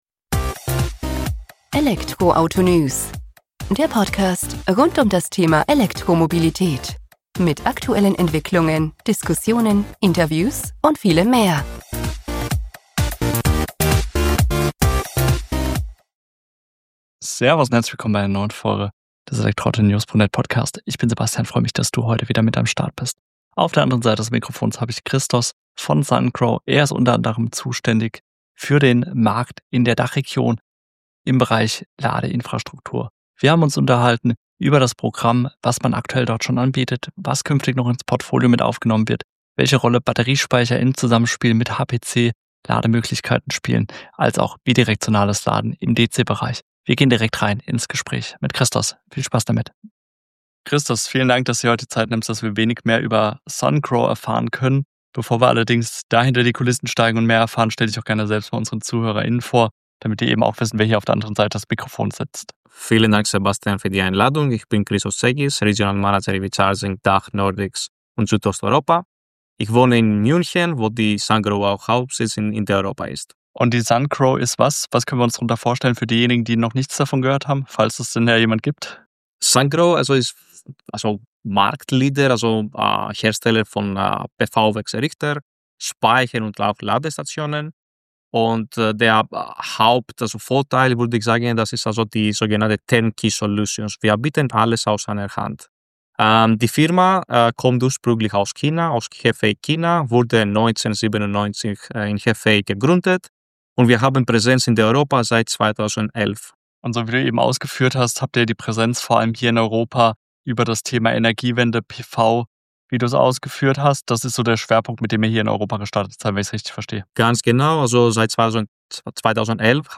Genau hier setzen wir im Gespräch an.